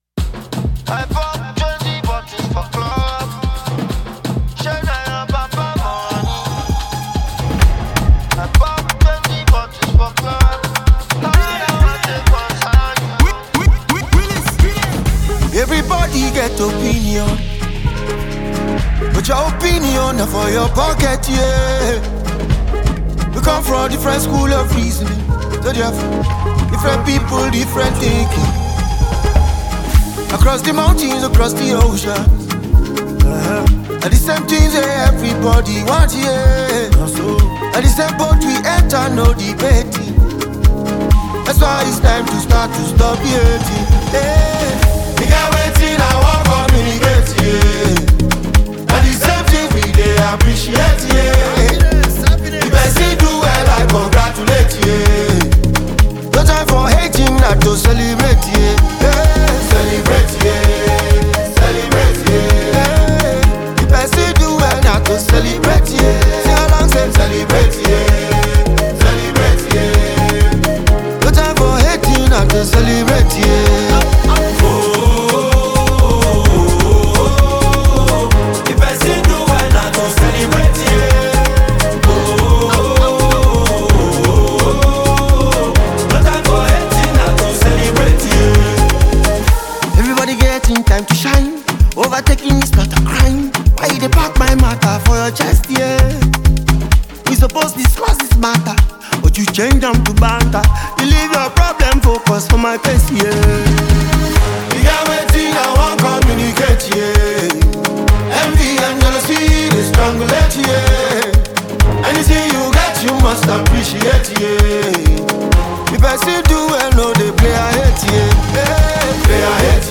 heartfelt anthem
With his soulful vocals and timeless message